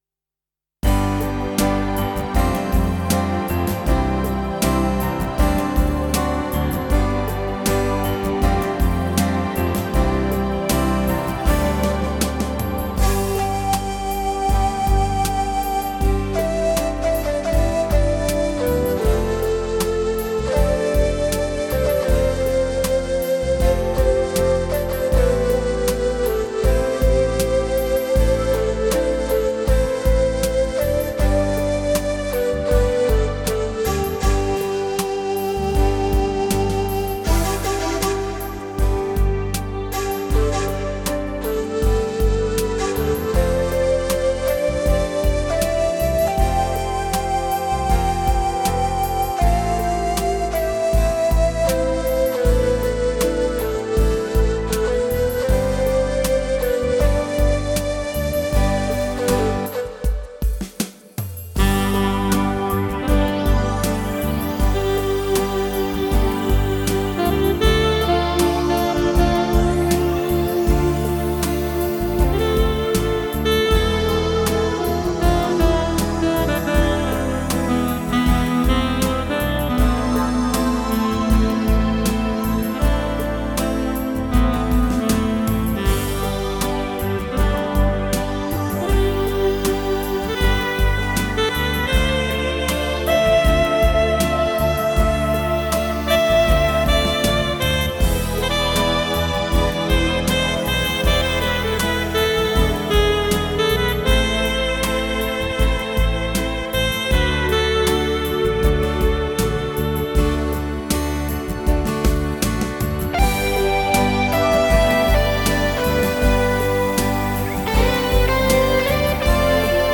Style 90Sposcardballad tempo 78 revoiçage de la voix d'origine 3 fois
Melodie et orchestration Voix : Shakuachi - Saxophone - Rocklegend&GuitarHero
et 4 voix avec effets du MOX6
Multipiste - Mixage - effets etc......